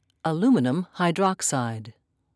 (a-lu'mi-num)